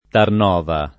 vai all'elenco alfabetico delle voci ingrandisci il carattere 100% rimpicciolisci il carattere stampa invia tramite posta elettronica codividi su Facebook Tarnova [ tarn 0 va ] o Ternova [ tern 0 va ] top. (Slovenia)